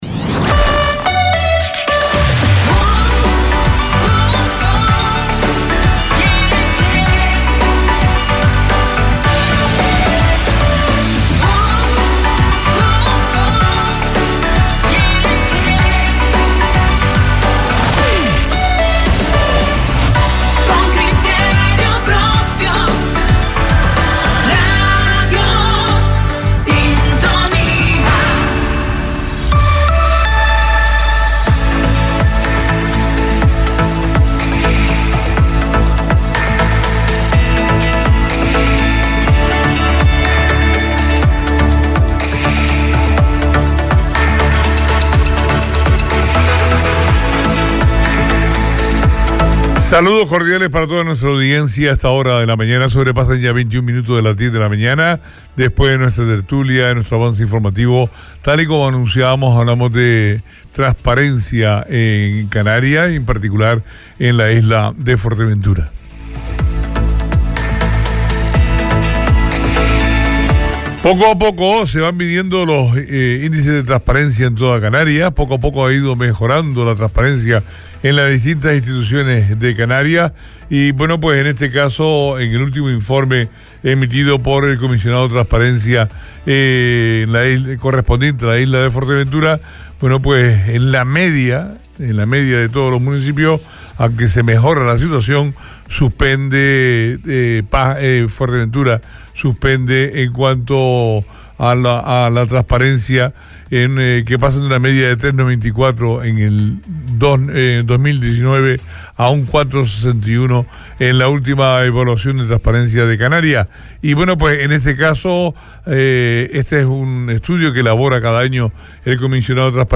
Entrevista a Daniel Cerdán, Comisionado de Transparencia de Canarias - 15.02.22 - Radio Sintonía